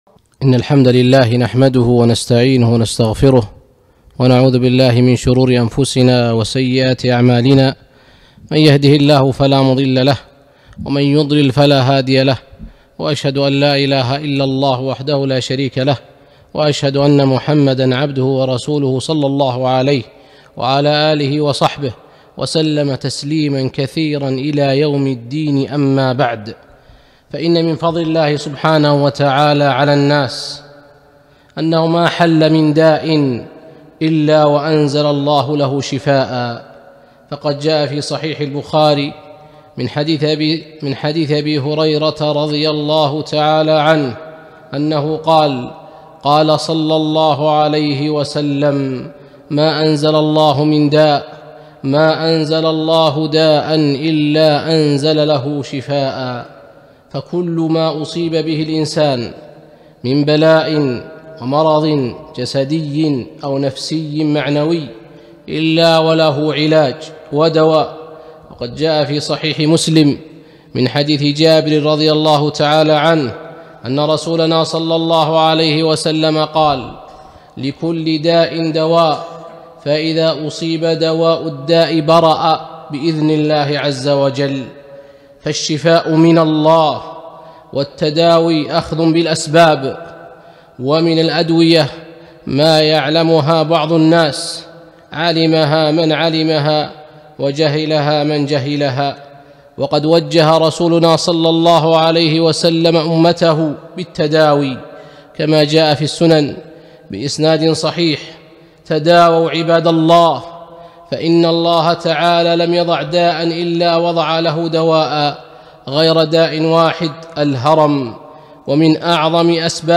محاضرة - مسائل وأحكام في الرقى